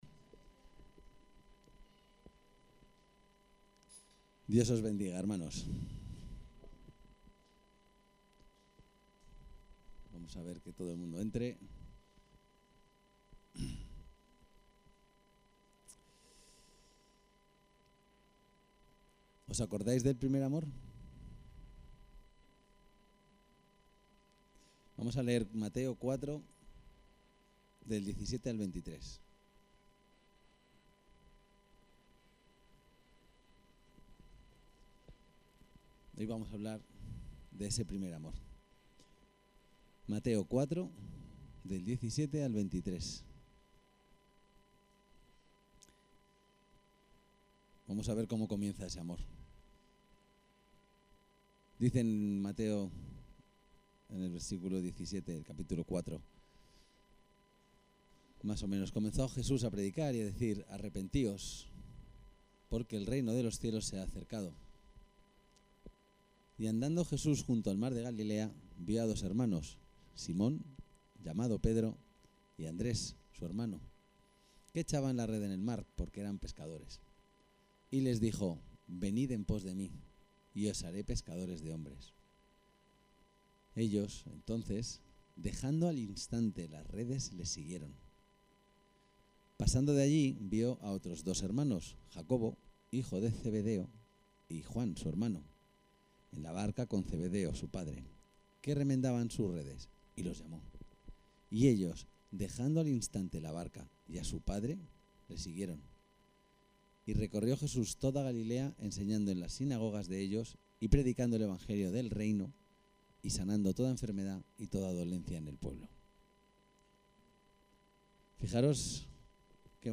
Este es el texto de la predicación => El_primer_amor